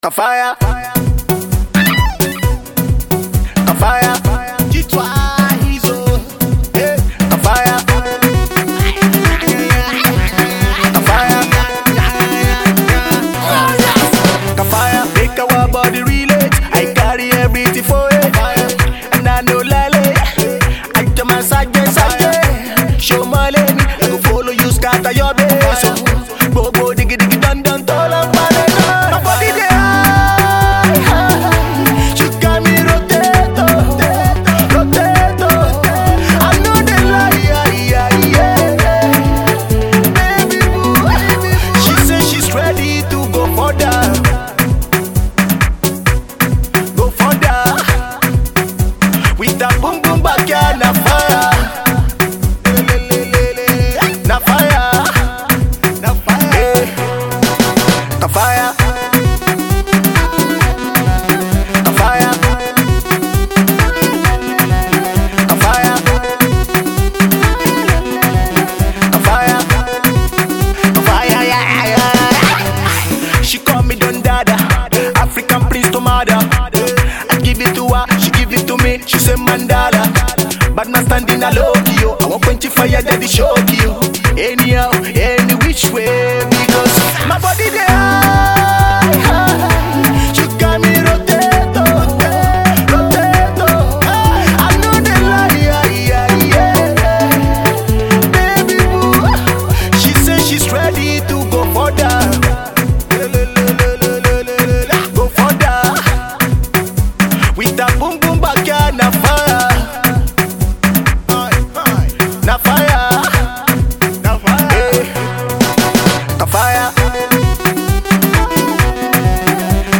Its quite a decent Pop Track